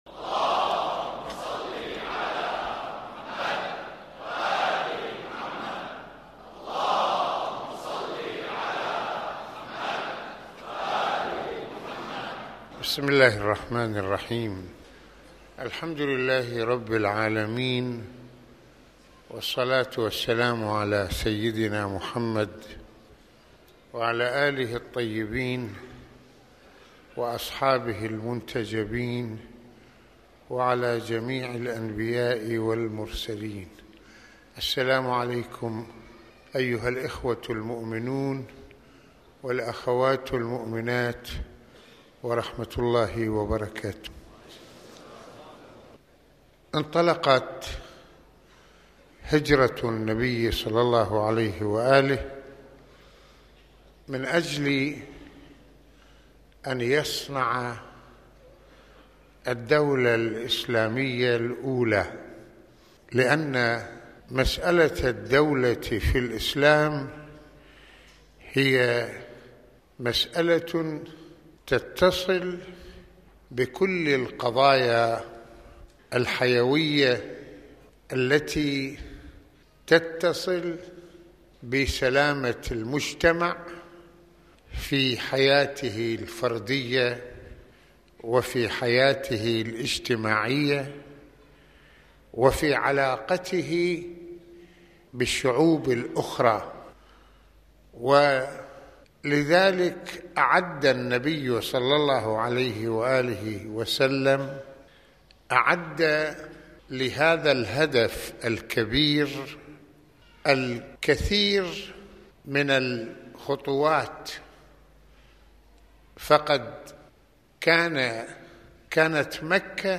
المناسبة : عاشوراء المكان : مسجد الإمامين الحسنين (ع)